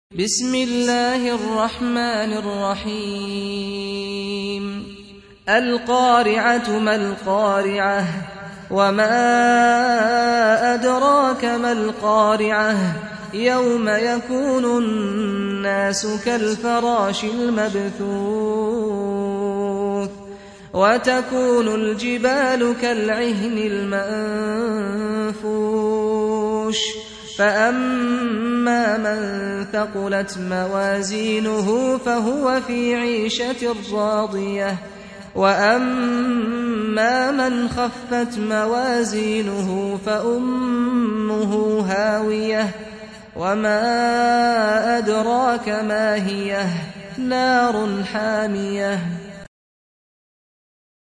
Surah Repeating تكرار السورة Download Surah حمّل السورة Reciting Murattalah Audio for 101. Surah Al-Q�ri'ah سورة القارعة N.B *Surah Includes Al-Basmalah Reciters Sequents تتابع التلاوات Reciters Repeats تكرار التلاوات